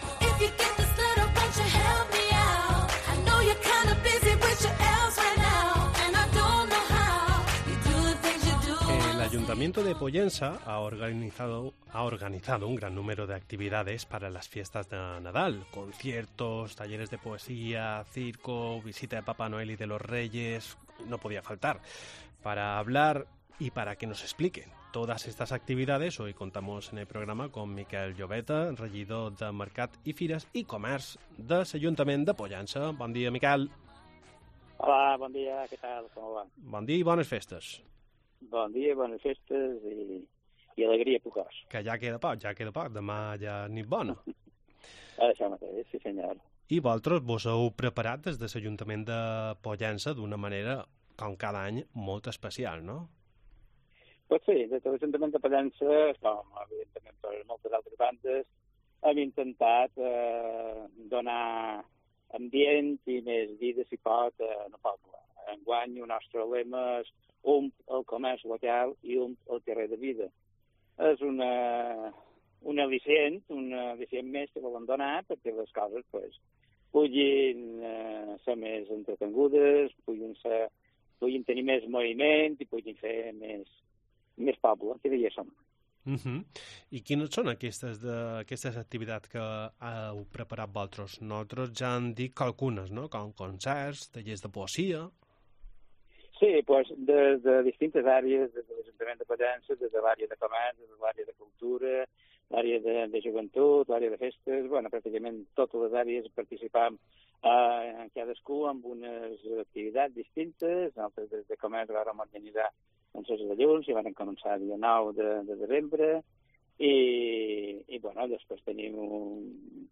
Charlamos en antena con Miquel Llobeta, Regidor de Mercat i Fires i comerç del Ayuntamiento de Pollença sobre las diferentes actividades que se llevarán a cabo este año